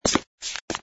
sfx_fturn_male01.wav